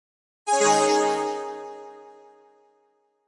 游戏音效 " FX166
描述：爆炸哔哔踢游戏gameound点击levelUp冒险哔哔sfx应用程序启动点击
Tag: 爆炸 单击 冒险 游戏 应用程序 点击的LevelUp 启动 gamesound 哔哔声 SFX